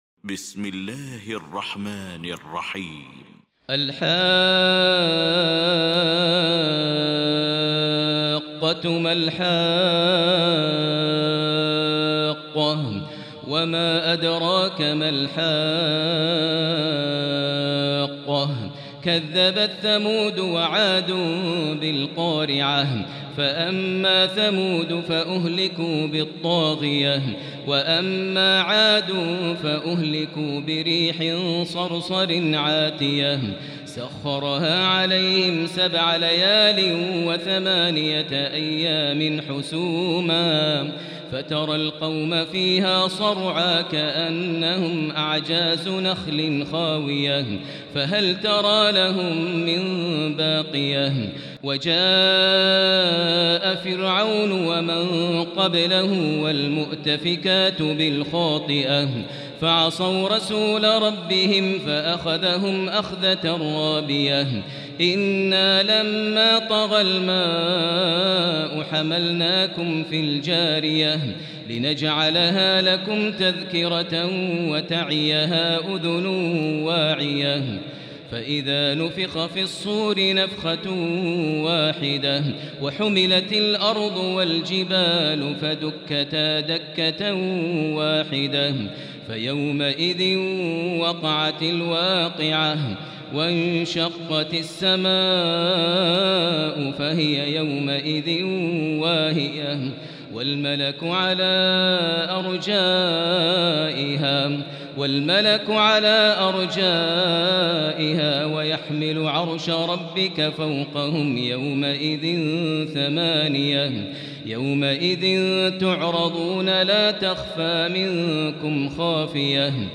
المكان: المسجد الحرام الشيخ: فضيلة الشيخ ماهر المعيقلي فضيلة الشيخ ماهر المعيقلي الحاقة